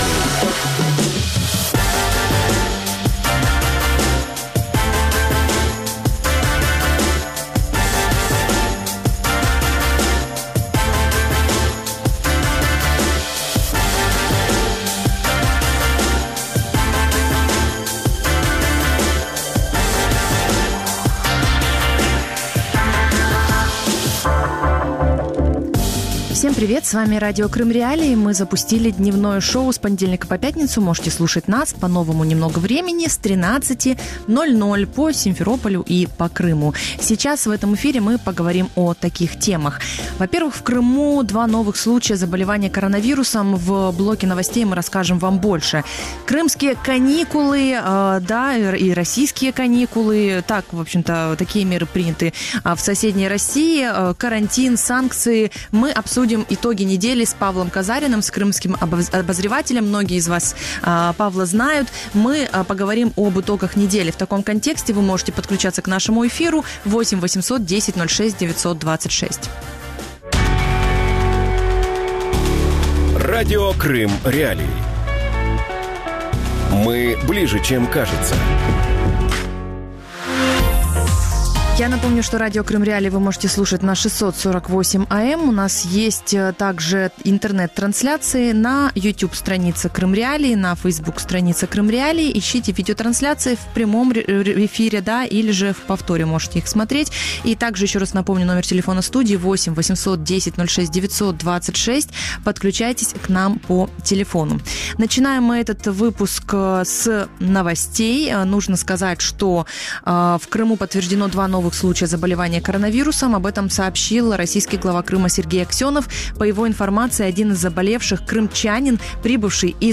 Крымские «каникулы» с карантином и санкциями | Дневное ток-шоу